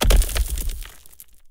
RockImpact.wav